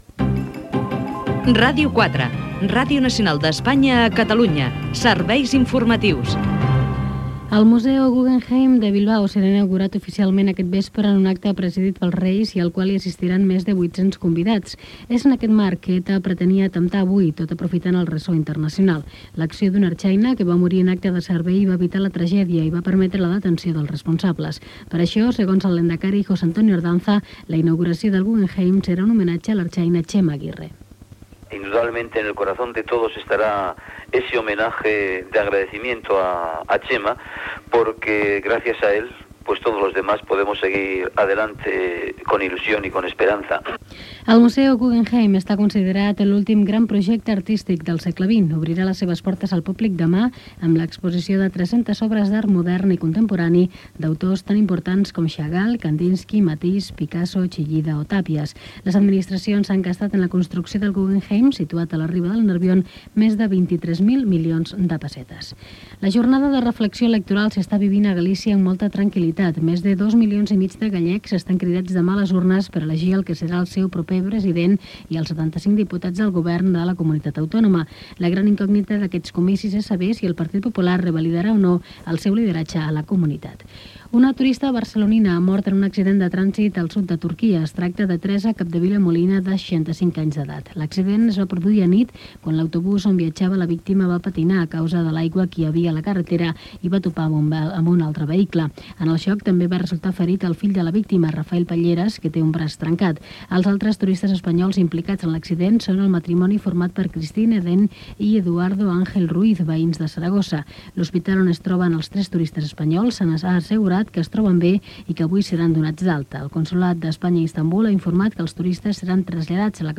Indicatiu del programa.
Informatiu
FM